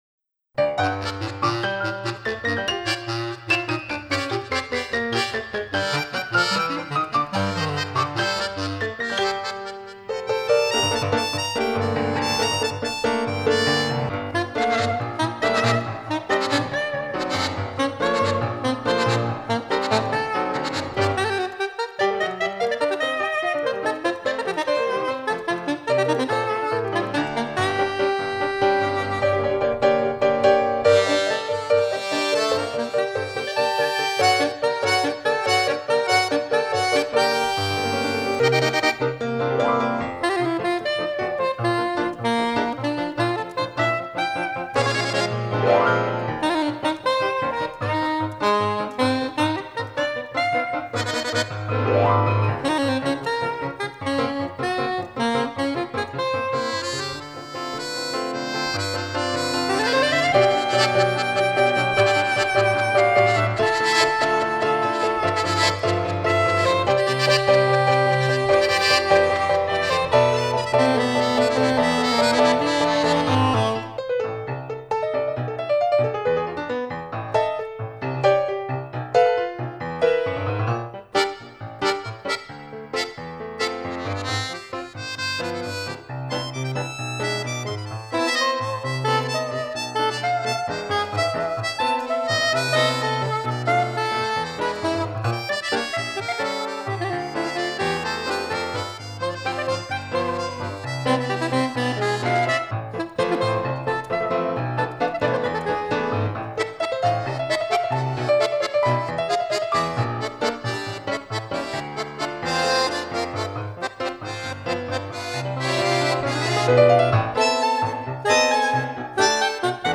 pianoforte
sax e clarinetto
fisarmonica